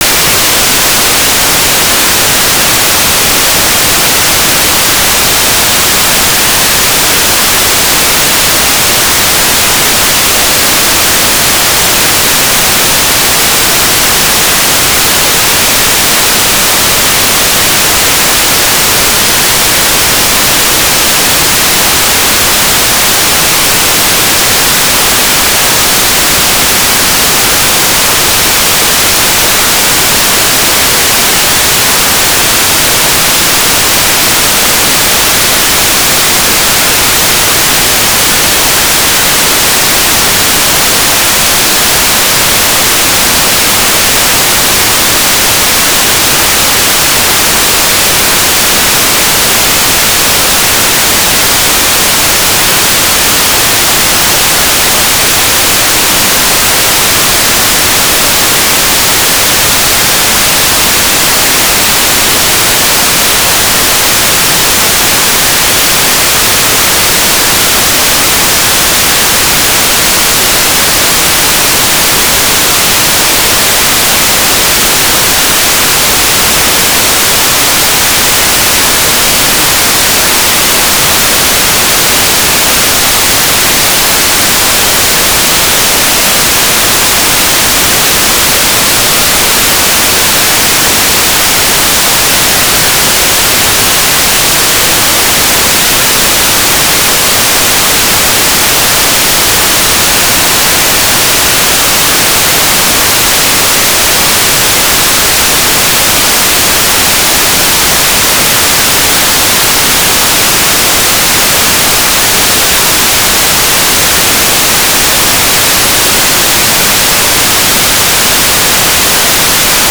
"transmitter_mode": "AFSK",